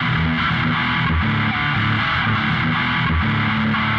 Tag: 120 bpm Dance Loops Guitar Electric Loops 689.11 KB wav Key : Unknown